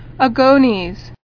[a·gon·es]